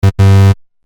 クイズ不正解 1 dry
ブブー